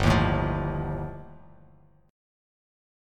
G7sus2#5 chord